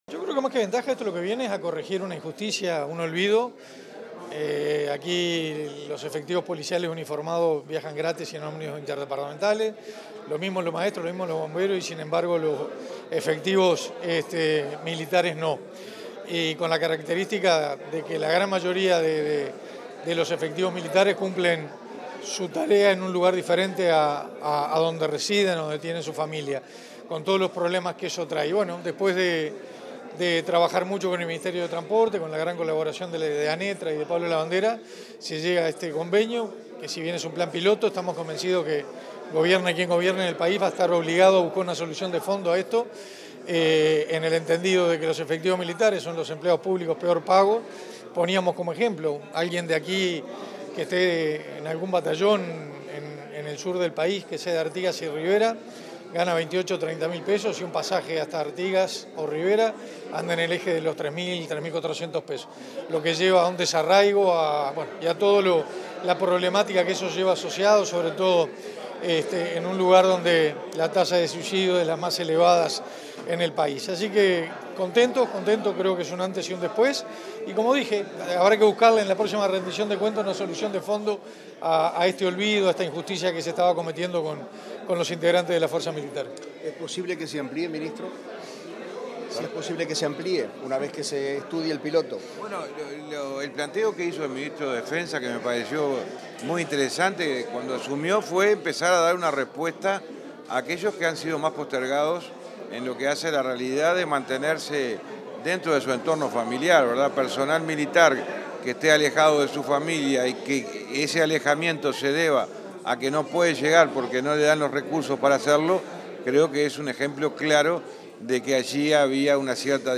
Declaraciones de los ministros de Transporte y de Defensa Nacional
Tras la firma de un convenio para efectivos militares en el marco de las políticas públicas que el Gobierno impulsa para contribuir a mejorar la salud mental y laboral de sus efectivos, este 27 de agosto, los ministros de Transporte y Obras Públicas, José Luis Falero, y de Defensa Nacional, Armando Castaingdebat fueron entrevistados por medios periodísticos.